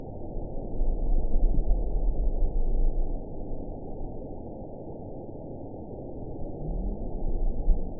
event 921336 date 05/10/24 time 12:43:26 GMT (11 months, 4 weeks ago) score 7.54 location TSS-AB06 detected by nrw target species NRW annotations +NRW Spectrogram: Frequency (kHz) vs. Time (s) audio not available .wav